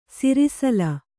♪ sirisala